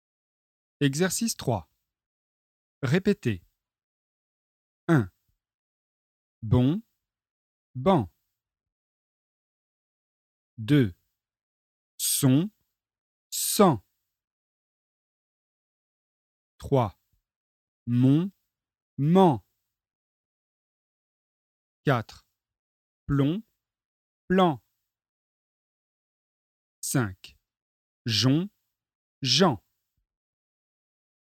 ★　Exercice 3 : répétez ( paires minimales ON/AN)